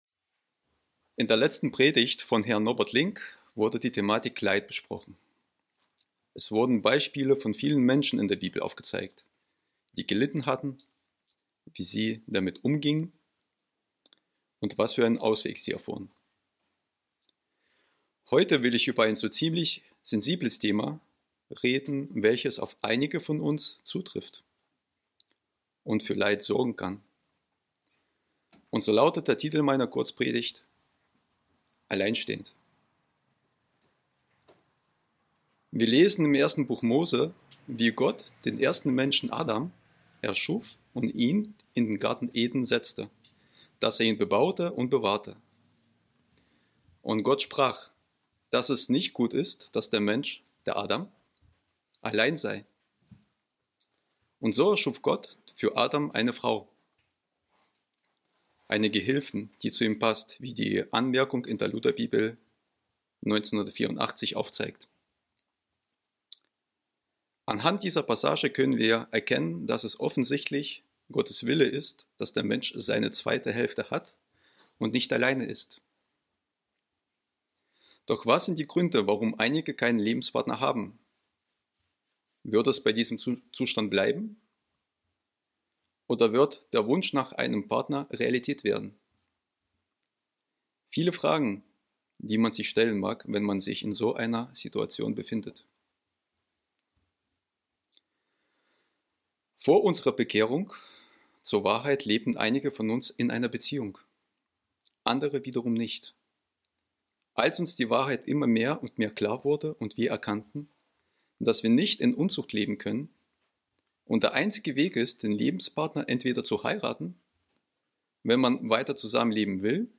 Viele sind alleinstehend und sehnen sich nach einer „zweiten Hälfte“. Diese Kurzpredigt zeigt Ihnen, was Sie persönlich tun können, um bereit für eine Ehe zu sein, und sie gibt Ihnen Trost, Ermutigung und Hoffnung.